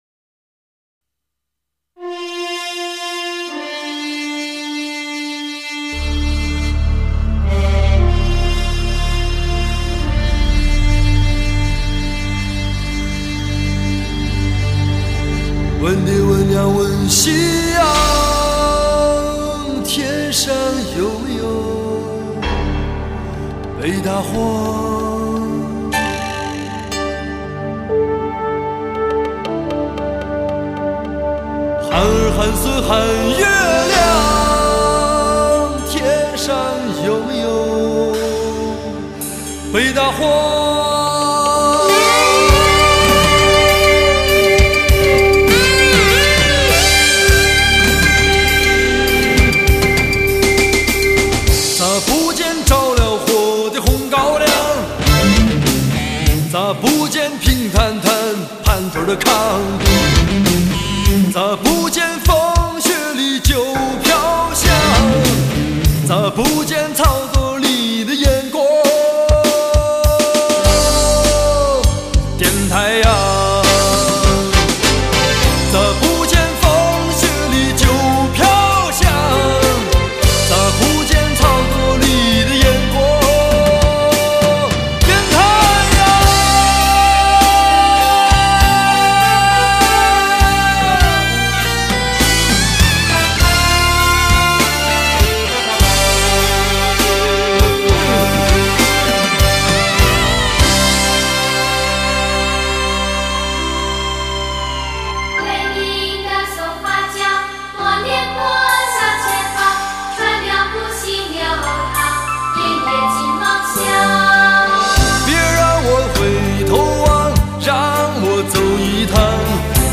在纯粹的男性味道中又不失灵动自由